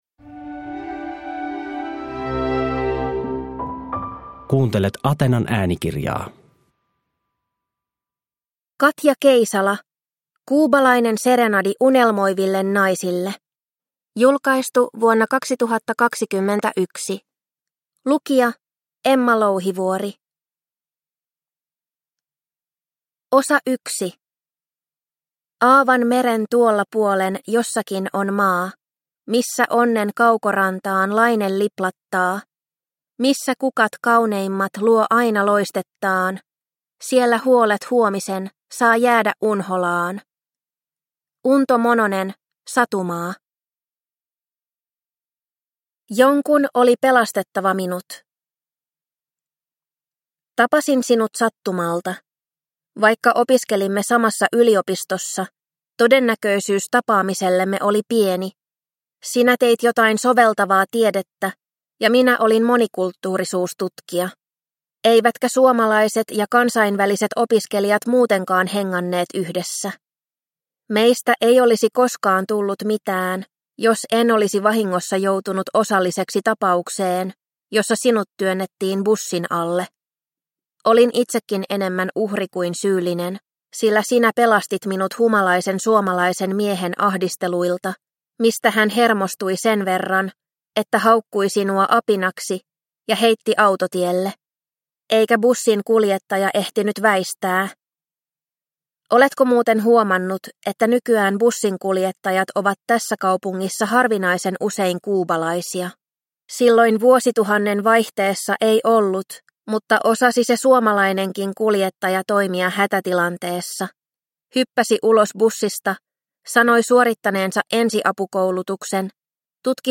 Kuubalainen serenadi unelmoiville naisille – Ljudbok – Laddas ner